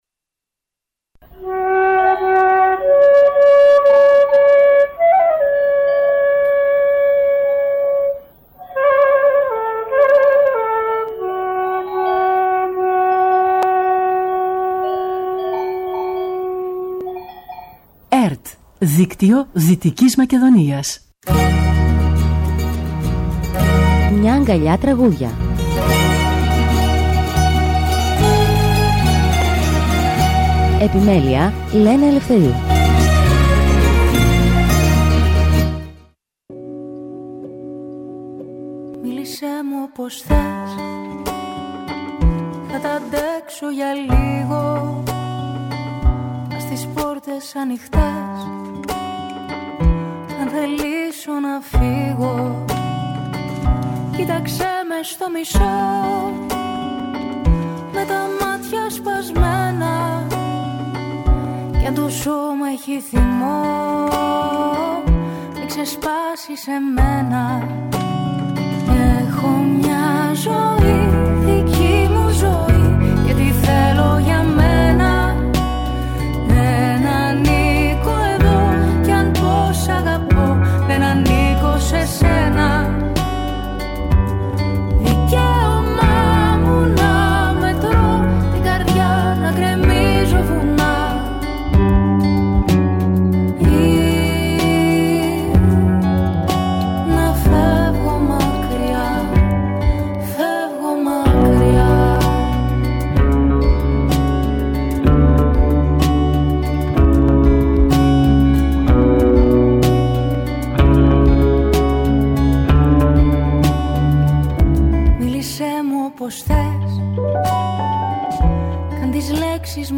Μουσική εκπομπή που παρουσιάζει νέες δισκογραφικές δουλειές, βιβλιοπαρουσιάσεις και καλλιτεχνικές εκδηλώσεις.
Συνεντευξη